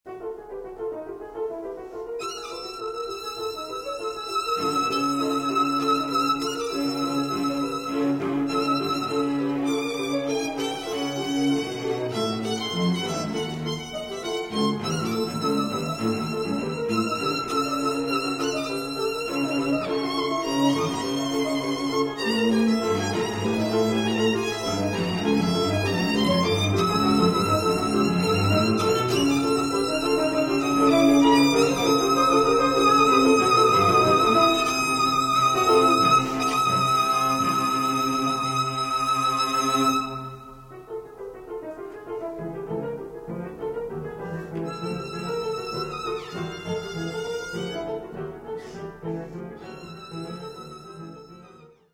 violine, violoncello, klavier
allegro